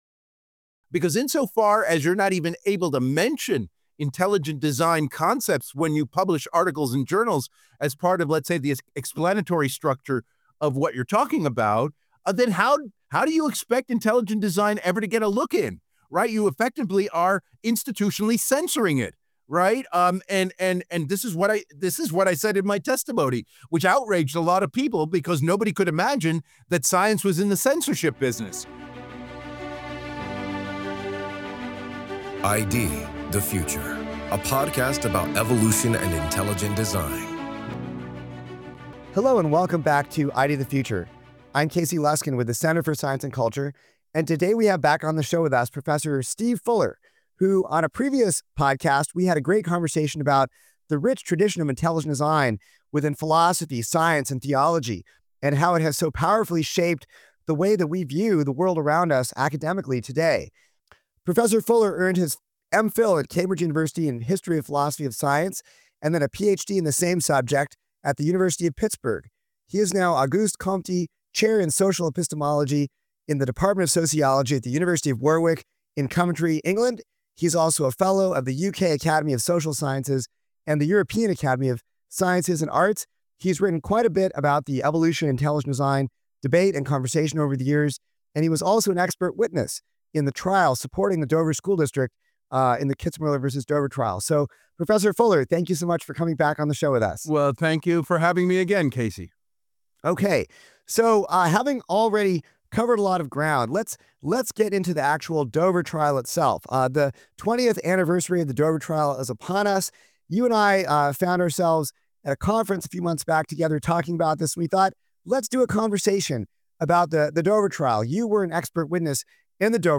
Guest(s) Steve Fuller